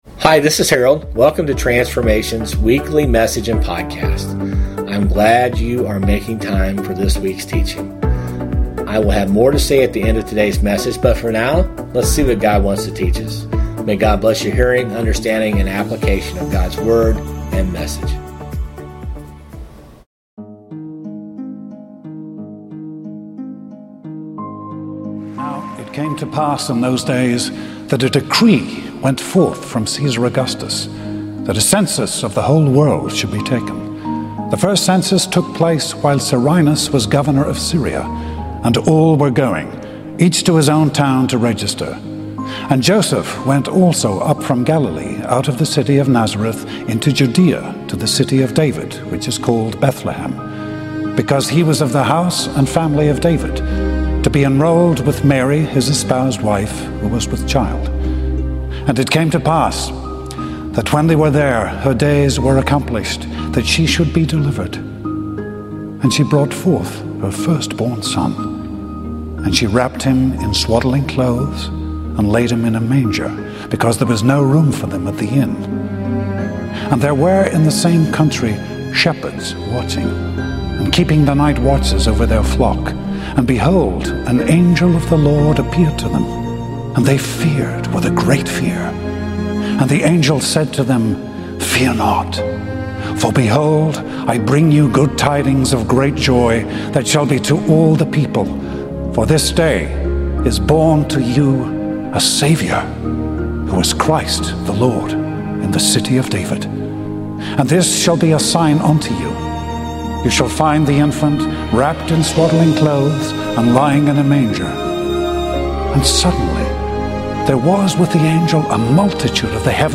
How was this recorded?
This Christmas Eve, we reflect on the extraordinary hope that God brings into the world through the birth of Jesus. In this powerful sermon, we explore how God chose the humble setting of a manger to reveal His love, grace, and redemption.